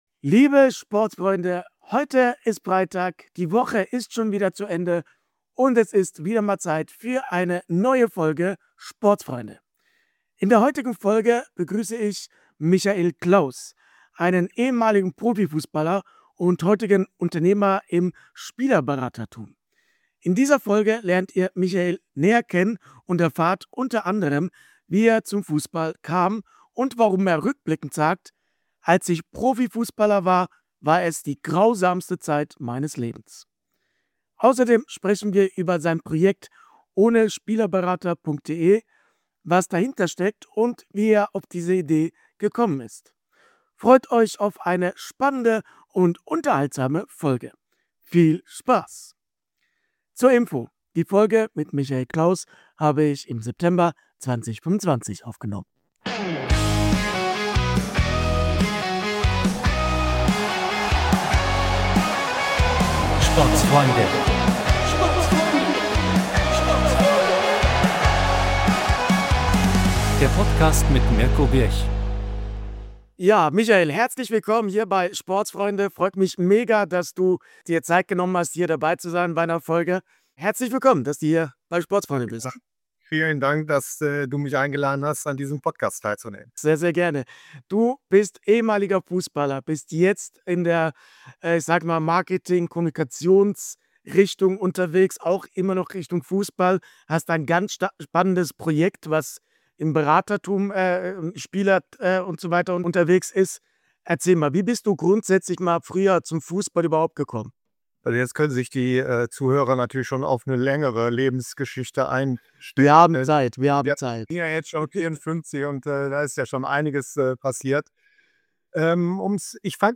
Zum Ende der Woche gibt es ein Gespräch, das den Fussball aus einer völlig anderen Perspektive zeigt.